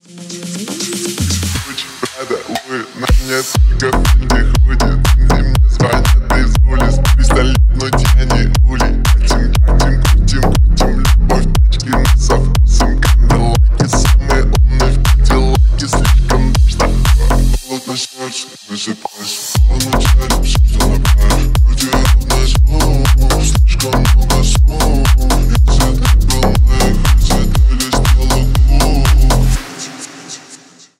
Рэп и Хип Хоп # Танцевальные
громкие